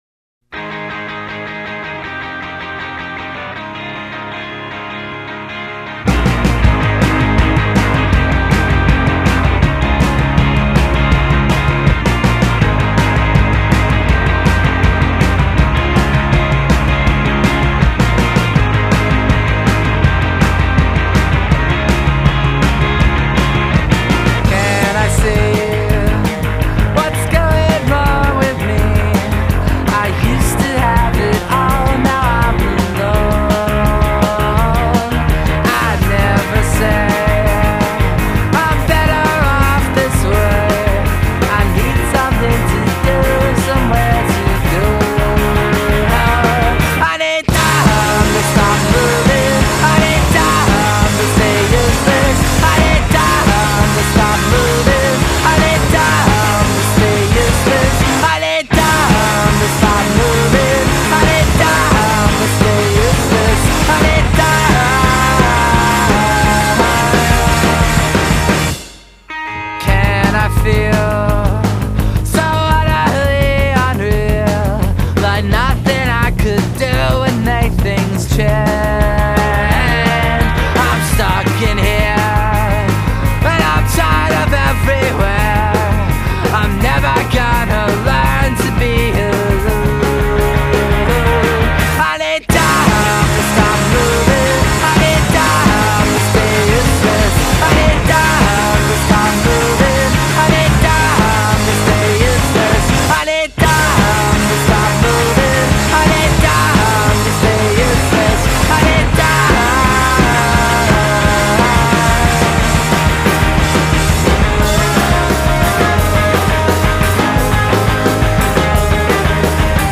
Music Review